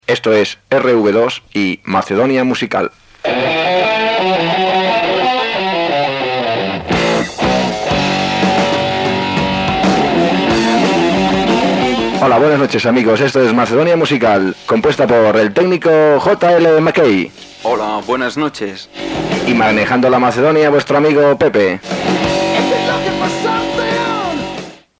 Identificació de l'emissora i presentació del programa amb els noms de l'equip
FM